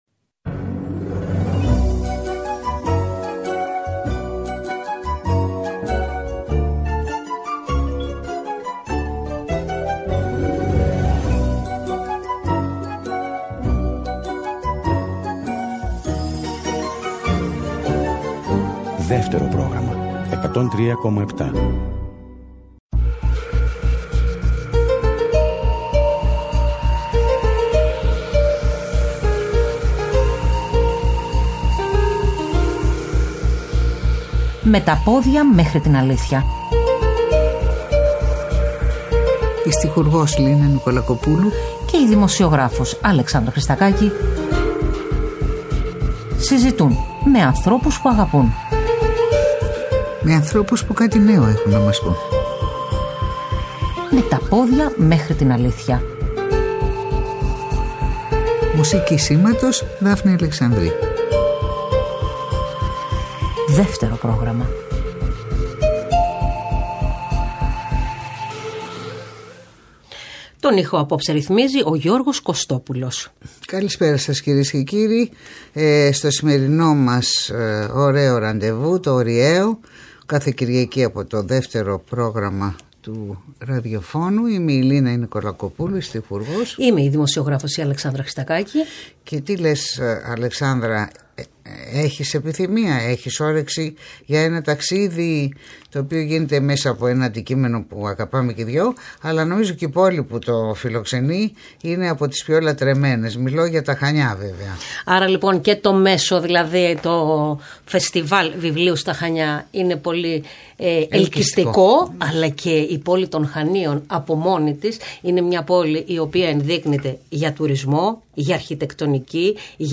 καλεσμένος στην εκπομπή ” Με τα πόδια μέχρι την αλήθεια” στις 18 Ιουνίου 2023.